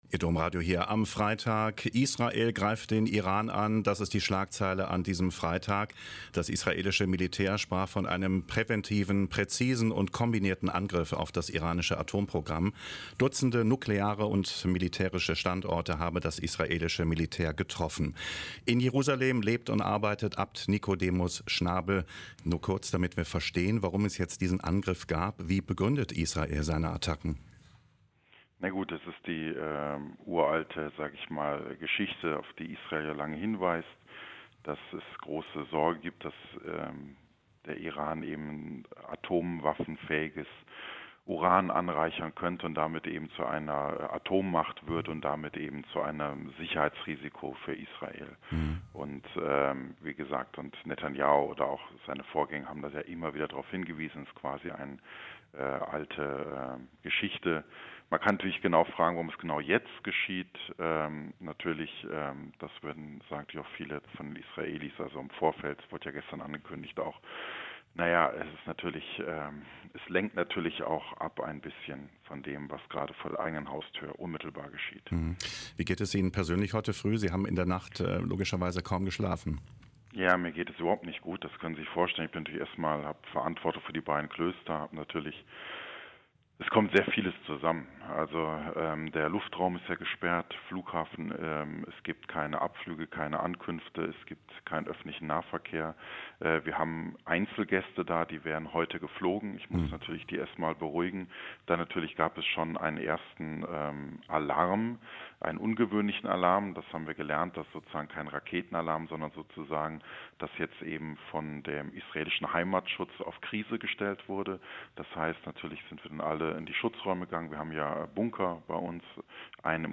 Ein Interview mit Nikodemus Schnabel (Benediktiner, Abt der Abtei der Dormitio in Jerusalem und des Priorats Tabgha am See Genezareth)